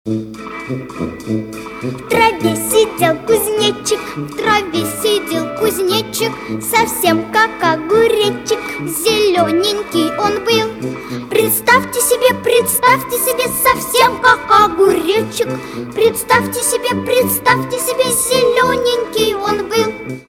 Песня из мультфильма